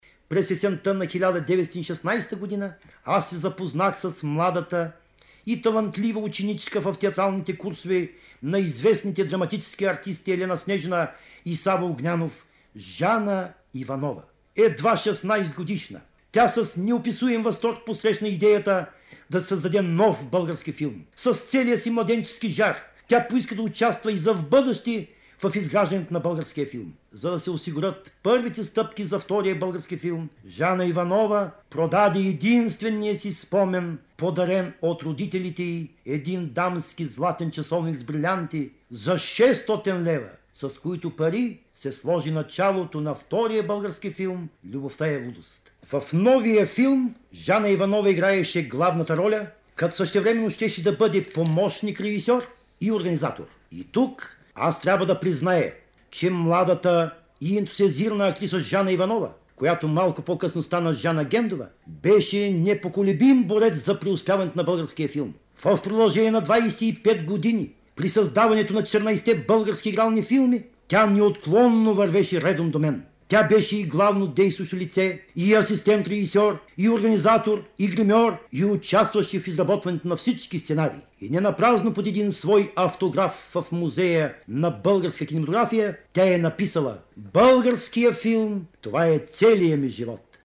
Васил Гендов разказва за „младата и талантлива ученичка“ в театралните курсове на Елена Снежина и Сава Огнянов, запис 1950 година, Златен фонд на БНР: